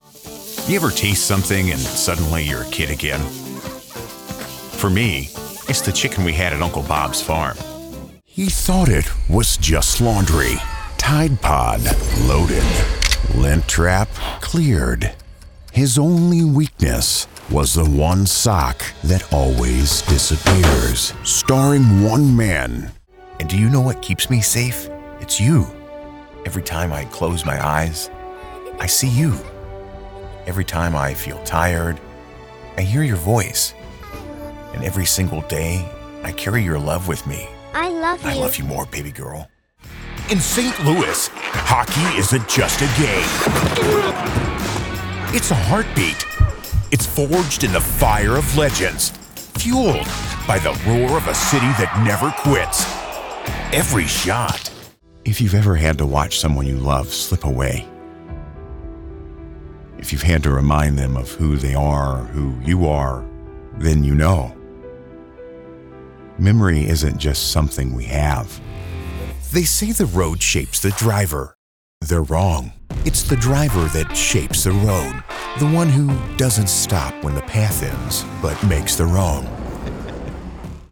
The One Voice Awards Nominated Commercial Demo
Young Adult
Middle Aged